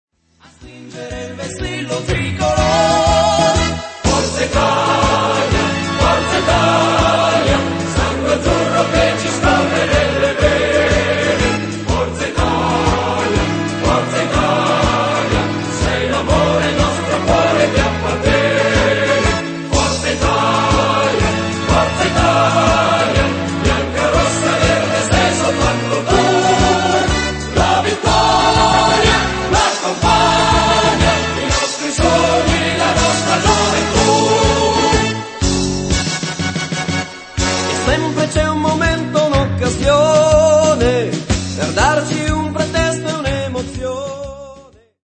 marcetta